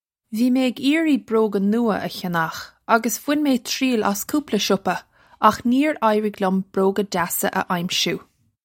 Pronunciation for how to say
Vee may ig eer-ree broh-ga new-a uh khyan-ukh uggus vwin may chree-ill ass koopla shuppa akh neer eye-rig lyum broh-ga jassa uh ime-shoo.
This is an approximate phonetic pronunciation of the phrase.